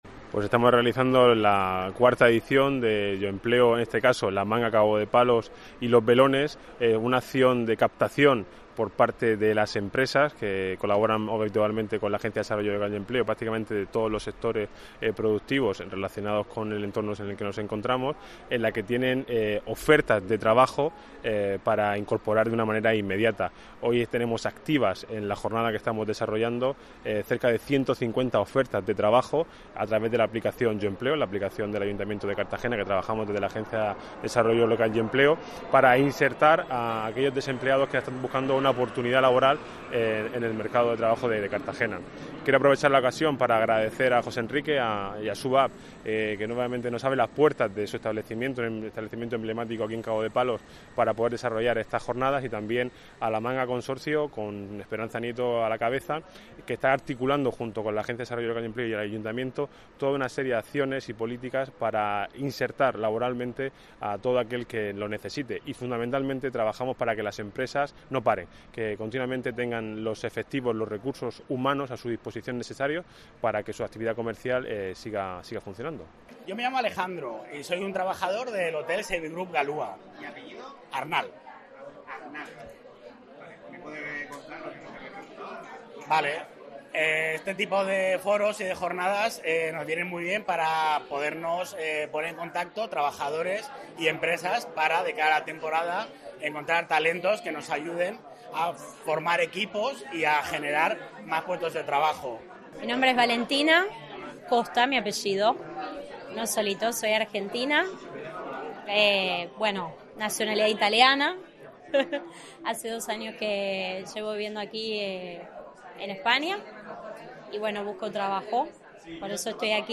Así se ha puesto de manifiesto este miércoles, 12 de marzo, en la celebración del IV Foro ADLE YOMPLEO, que aglutina también a Los Belones y el campo de golf.